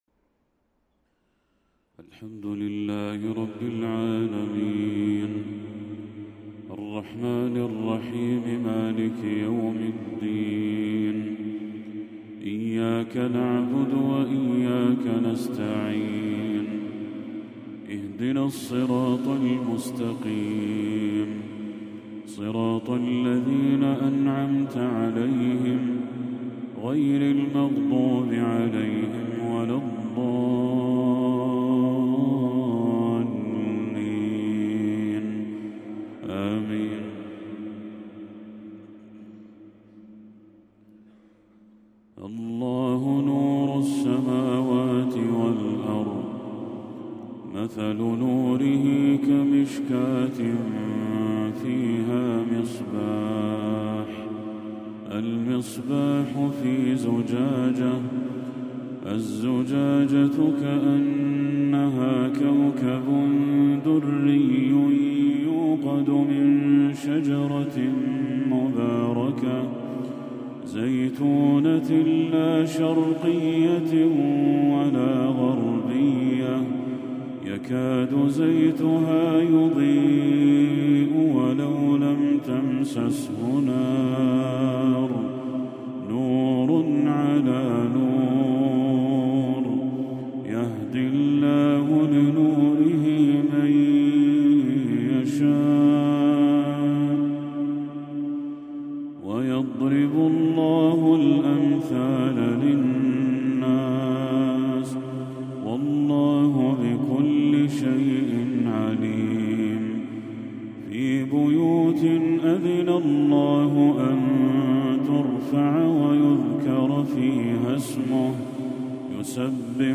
تلاوة أخَّاذة بالأداء الشهير من سورة النور للشيخ بدر التركي | عشاء 27 ربيع الأول 1446هـ > 1446هـ > تلاوات الشيخ بدر التركي > المزيد - تلاوات الحرمين